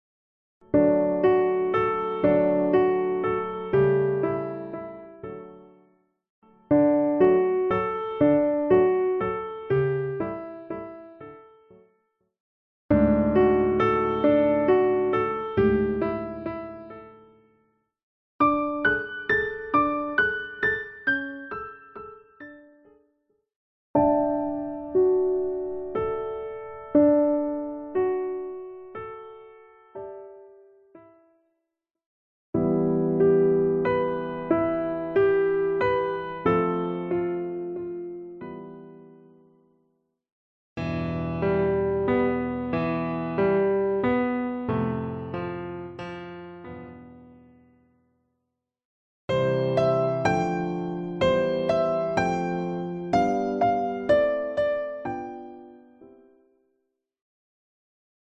Level 1-Easy Piano Solos
chords, tone clusters, broken chords,